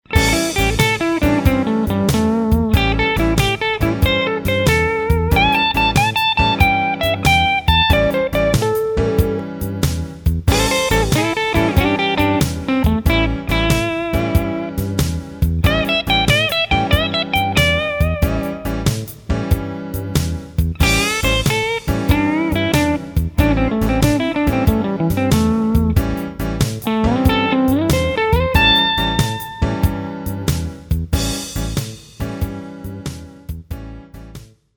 Come primo esempio pratico osservate la partitura che vedete sotto, in cui viene sviluppato un solo molto semplice sulla scala pentatonica minore di A, il tutto suonato su di un pedale di A7 con ritmica terzinata. A tal proposito ricordate che gli ottavi vengono suonati con la ritmica shuffle.
Example solo on A7 pedal Example solo on A7 pedal